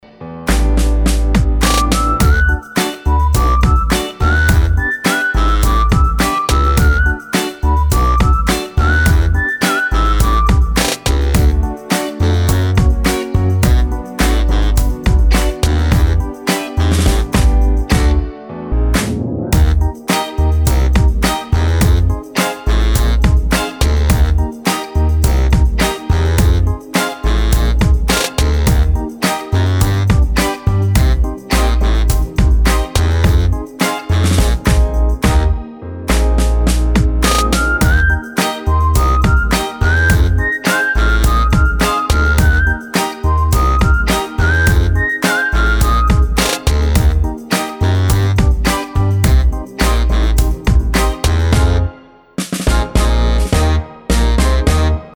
• Качество: 320, Stereo
свист
зажигательные
веселые
без слов
инструментальные
Саксофон
Прикольный мотивчик со свистом